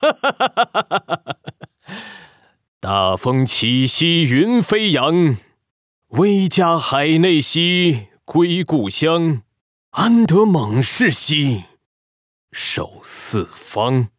配音试听 男性角色配音